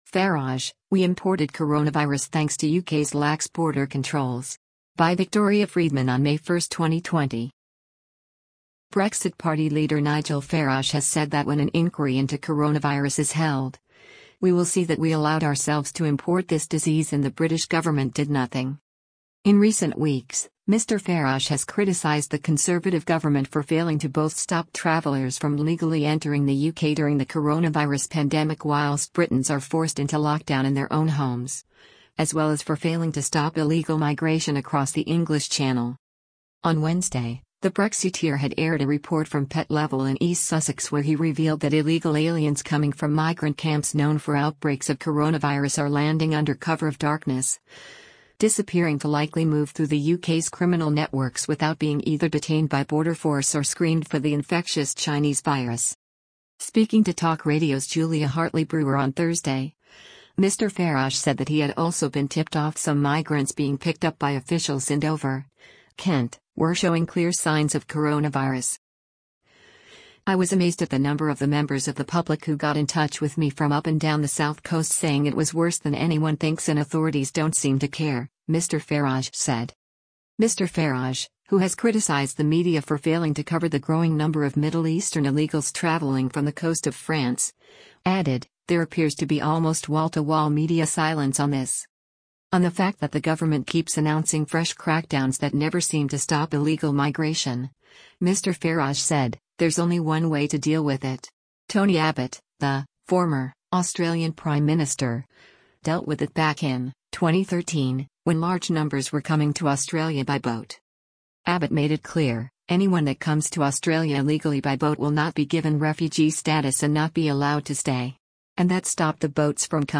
Speaking to talkRADIO’s Julia Hartley-Brewer on Thursday, Mr Farage said that he had also been tipped off some migrants being picked up by officials in Dover, Kent, were showing clear signs of coronavirus.